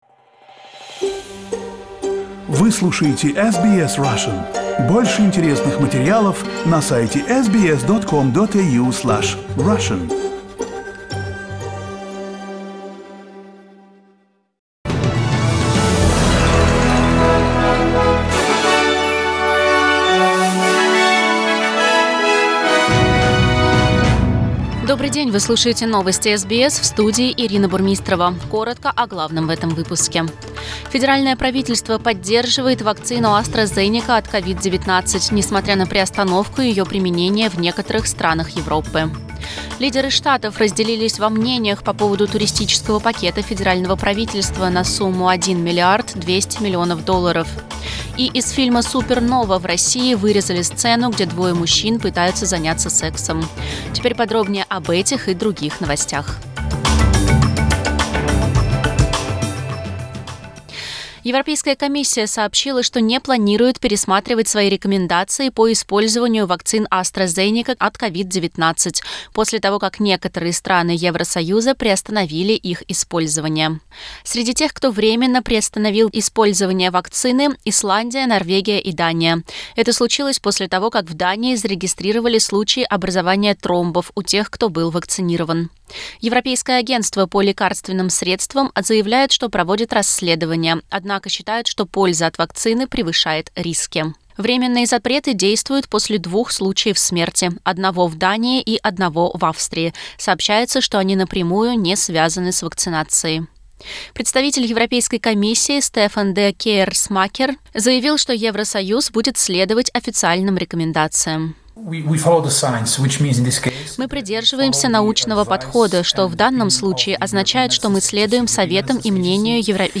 News bulletin in Russian - 12.03